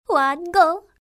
Go_1_Voice.mp3